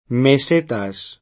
meseta [sp. meS%ta] s. f. (geogr.); pl. mesetas [
meS%taS] — con M- maiusc., e anche con pn. italianizz. [me@$ta], se inteso come top.: il vasto altopiano della Spagna interna (Castiglia e altre regioni)